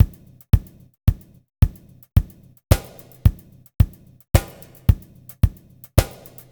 WVD DRUMS -L.wav